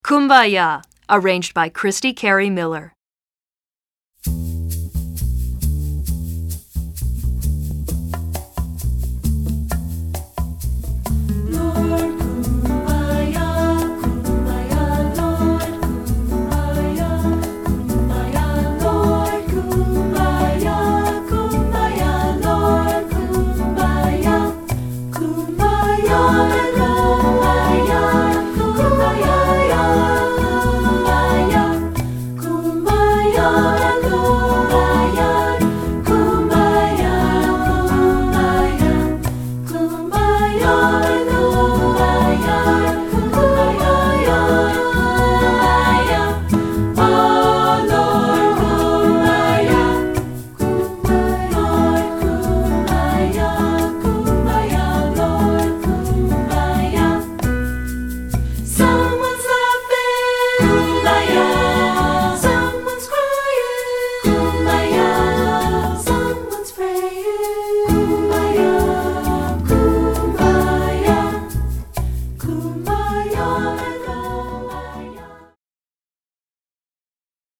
Voicing: 3-Part